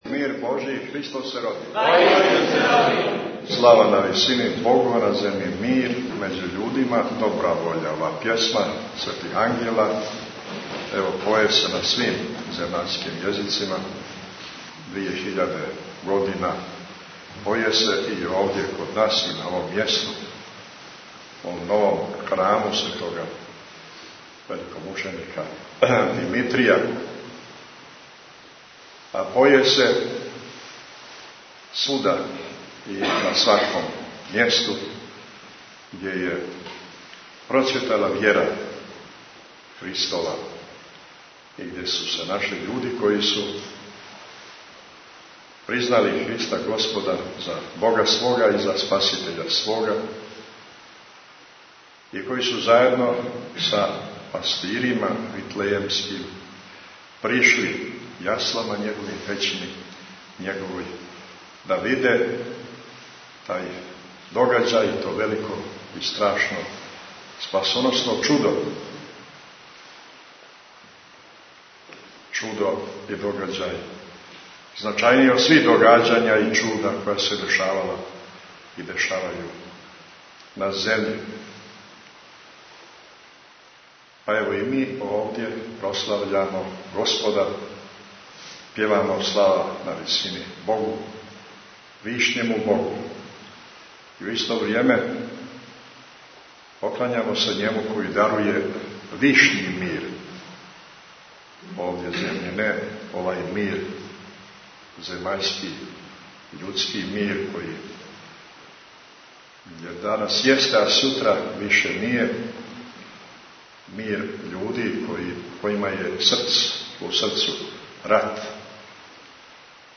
Бесједа Његовог Високопреосвештенства Архиепископа Цетињског Митрополита Црногорско-приморског Г. Амфилохија са Свете Архијерејске Литургије коју је на празник Сабора Светог Јована Крститеља-Јовањдан, служио у Цетињском манастиру поред Десне руке Светог Пророка Претече и Крститеља Господњег